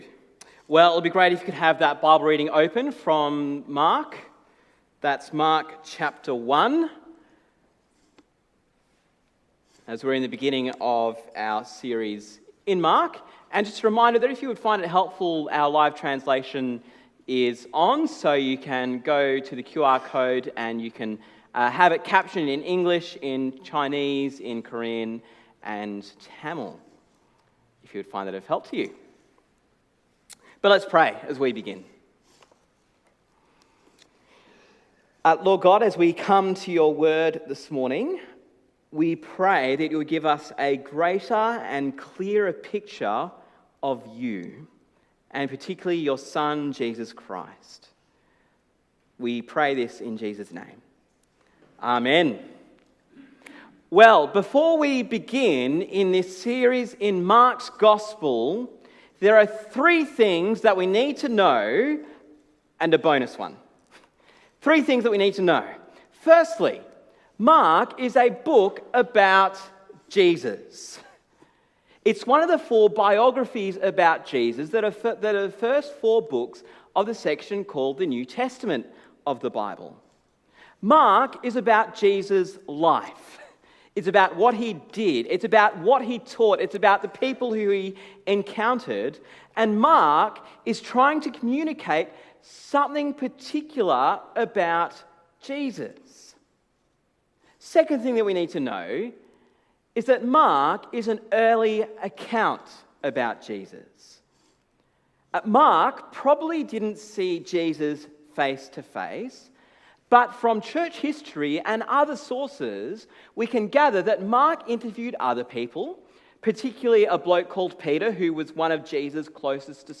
Sermon on Mark 1:1-13 - The Gospel of Jesus Christ